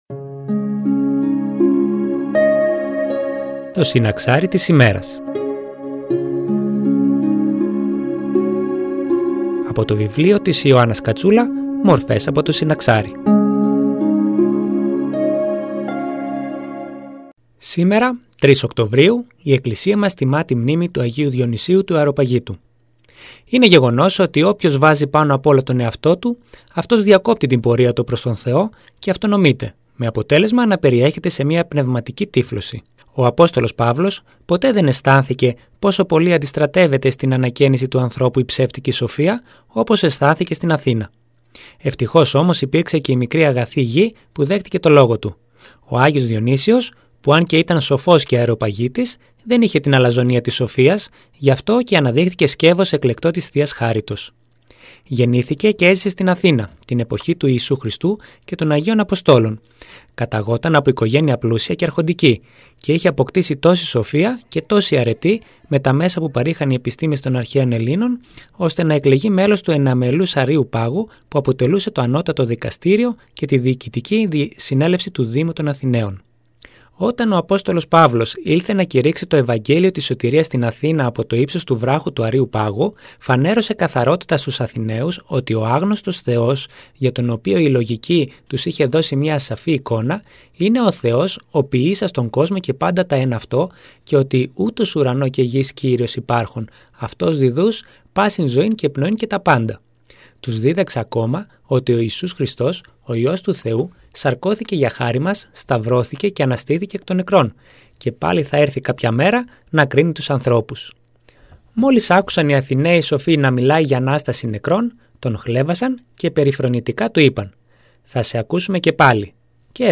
Μια ένθετη εκπομπή που μεταδίδεται από Δευτέρα έως Παρασκευή στις 09:25 από την ΕΡΤ Φλώρινας.
Εκκλησιαστική εκπομπή